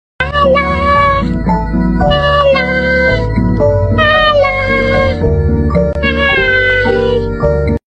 hello-CAT.mp3